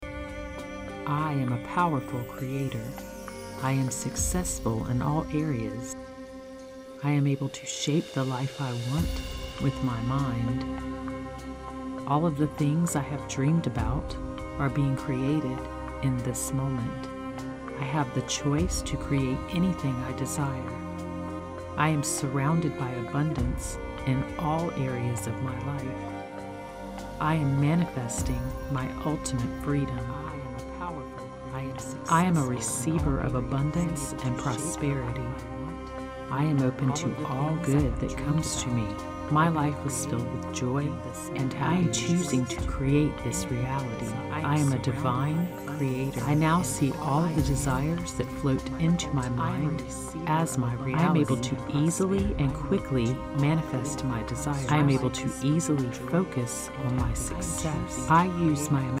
This meditation has over 2000 affirmation impressions (both subliminal and non-subliminal) to program your mind and build your belief in manifesting as the powerful creator you are!
It is combined with a 528 Hz binaural tone, known as the “Miracle Tone” to assist you in manifesting miracles and promoting a calm and confident state. The affirmations move from ear to ear and back to center to strongly infuse the messages.